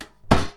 shield-hit-7.ogg